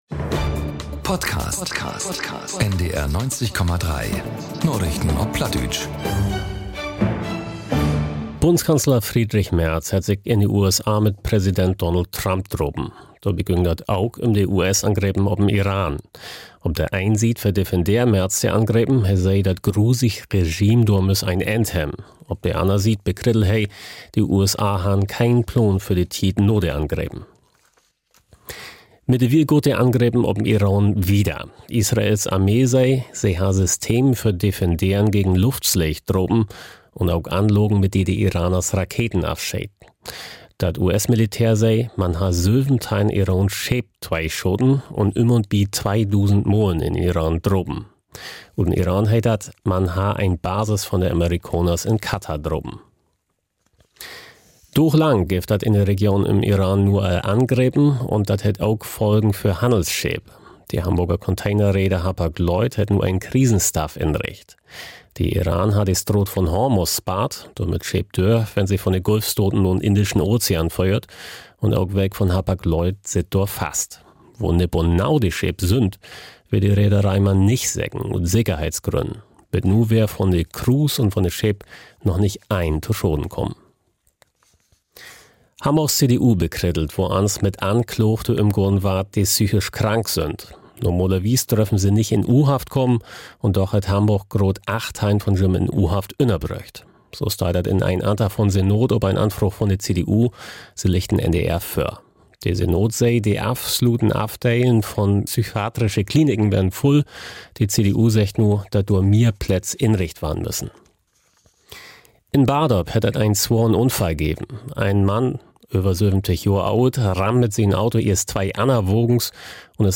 Narichten op Platt 04.03.2026 ~ Narichten op Platt - Plattdeutsche Nachrichten Podcast